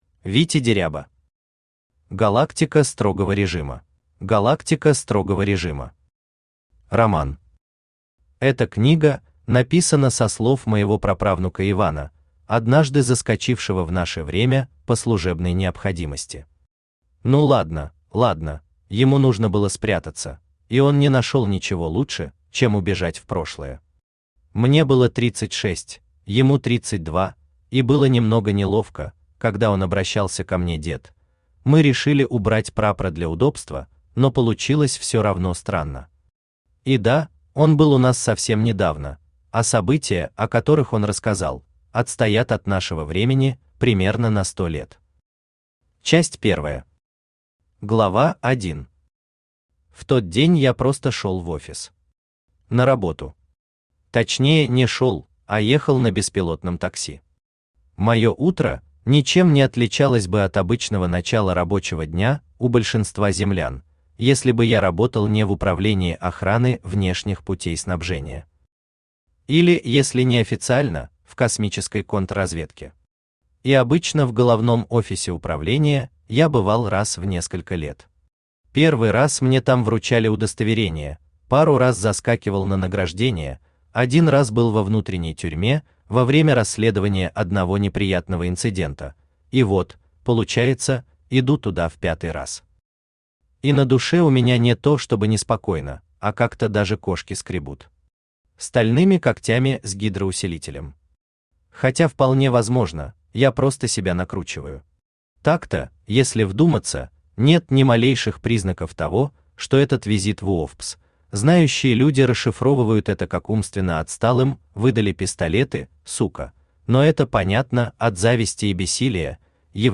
Аудиокнига Галактика строгого режима | Библиотека аудиокниг
Aудиокнига Галактика строгого режима Автор Витя Деряба Читает аудиокнигу Авточтец ЛитРес.